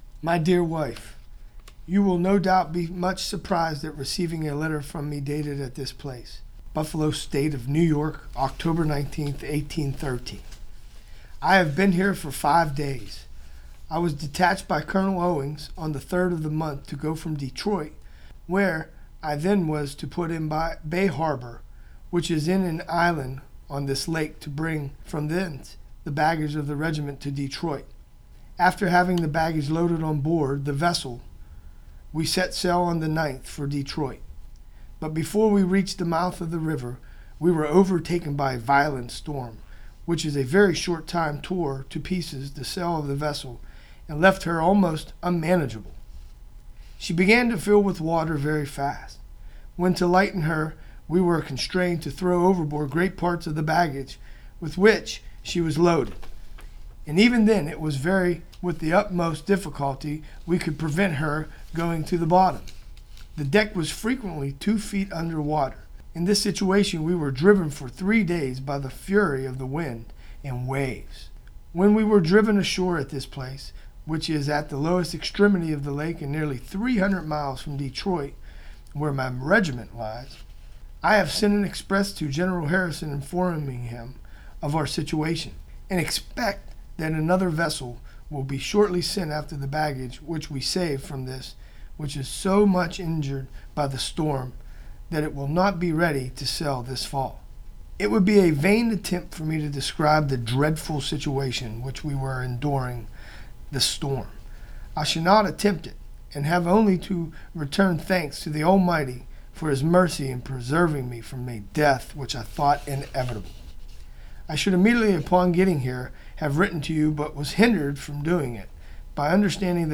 We are truly honored to have local U.S. military veterans from different backgrounds read portions of the Letters Home Collection from The Mariners’ Museum Library.